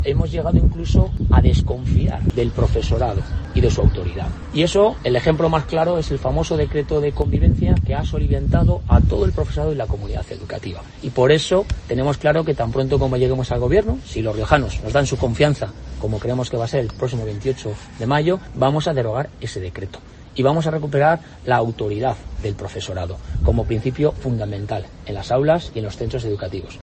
Capellán ha realizado estas afirmaciones junto al colegio de Medrano, durante una rueda de prensa en la que ha presentado las propuestas del Partido Popular en materia educativa